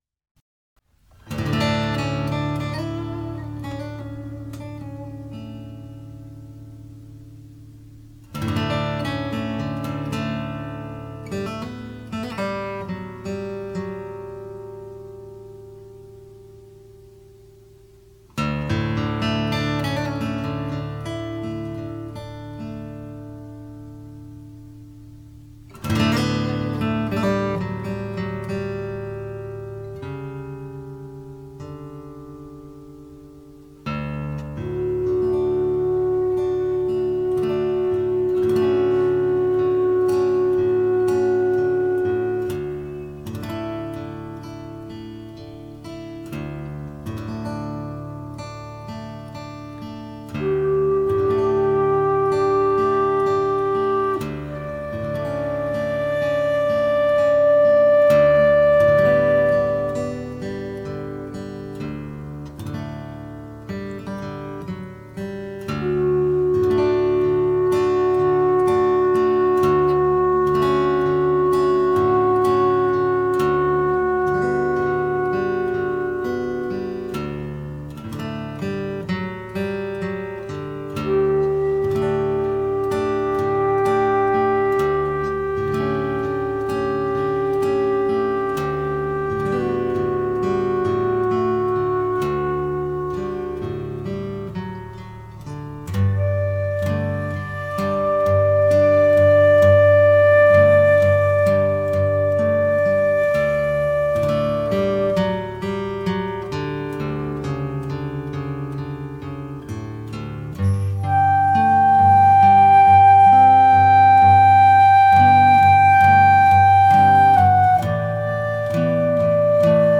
ten behoeve van het sabbatseminar “Het leven vieren”